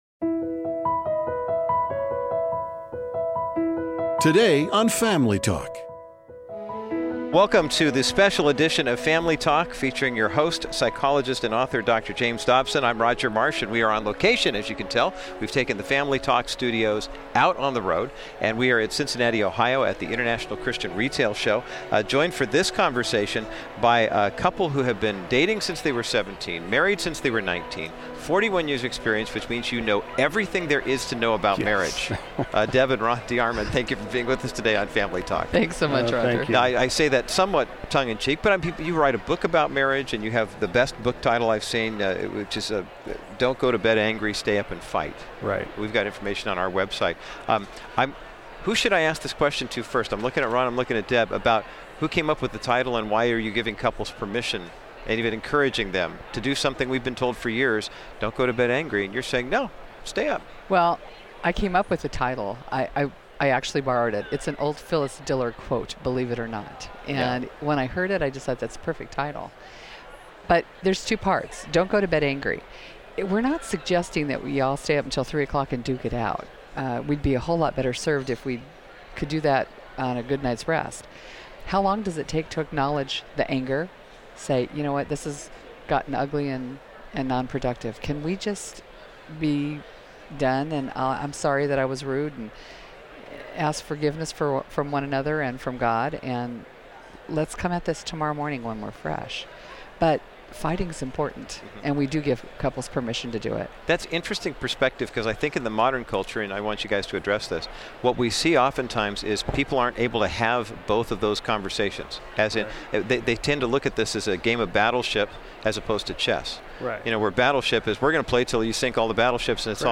You dont want to miss this eye-opening discussion today on Family Talk.